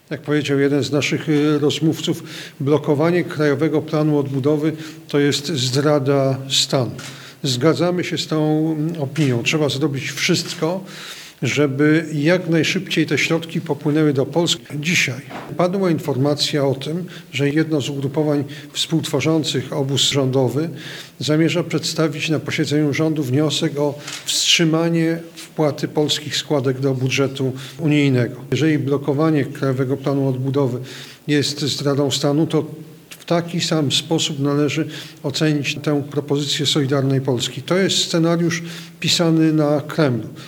Podczas konferencji prasowej Gowin odniósł się do stwierdzenia jednego z przedsiębiorców, że blokowanie KPO jest zdradą stanu.